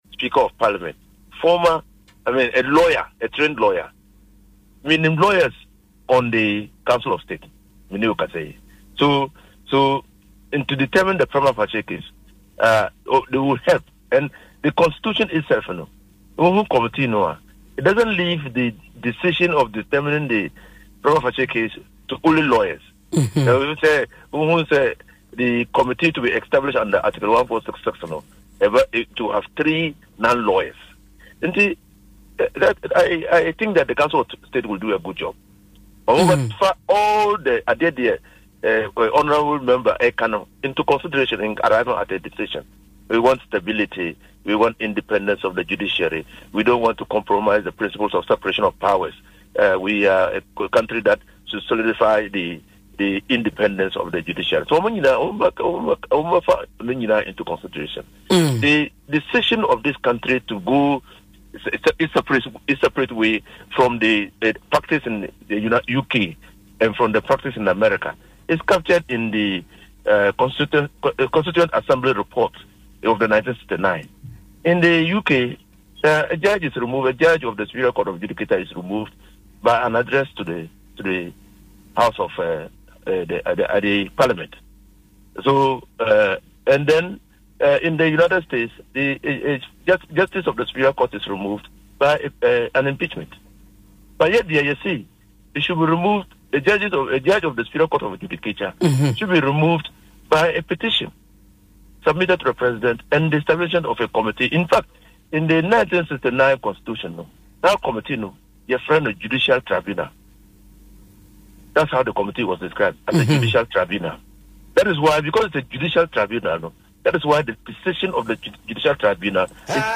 Speaking on Dwaso Nsem on Adom FM, the lawyer noted that his confidence stems from the composition of the current Council of State.